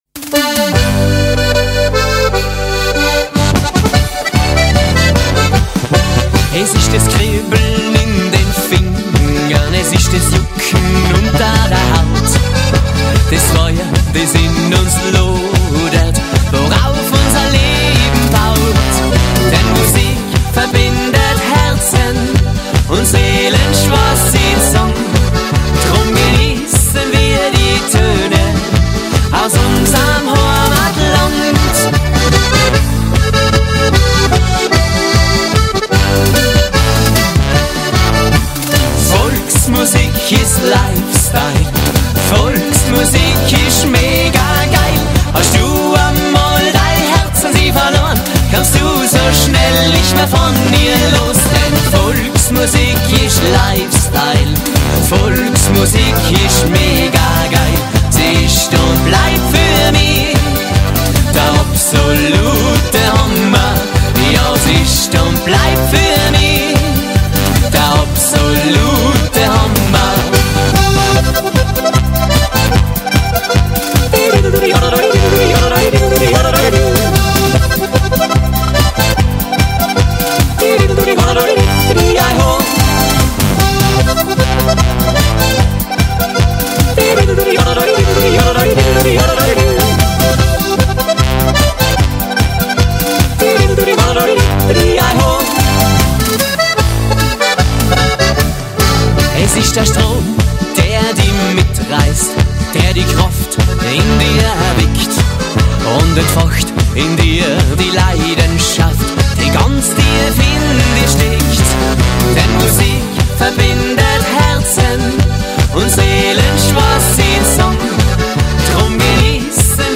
Musik made in Tirol für überall
Volksmusik mit Vollgas und